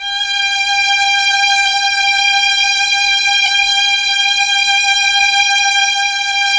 Index of /90_sSampleCDs/Roland LCDP09 Keys of the 60s and 70s 1/KEY_Chamberlin/STR_Chambrln Str